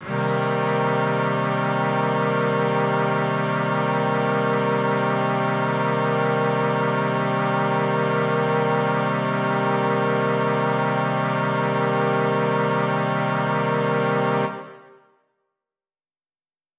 SO_KTron-Cello-Cmin7.wav